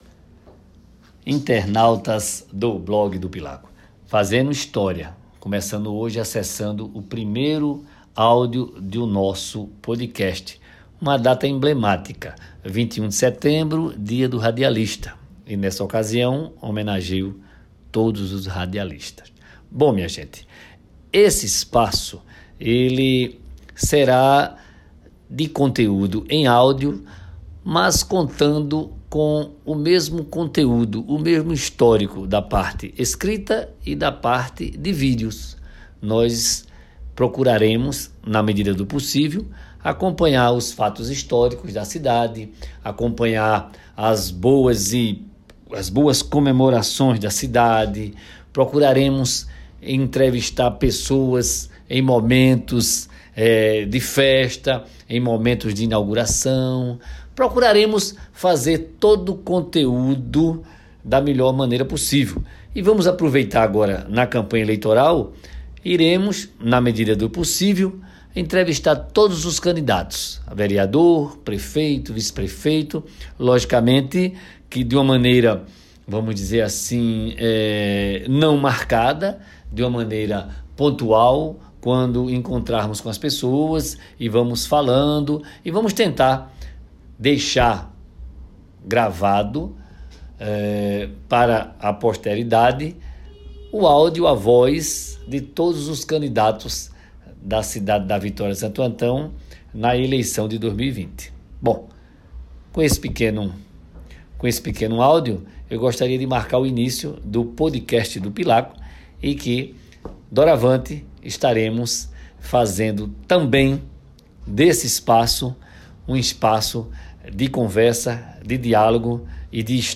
ao vivo no cd e dvd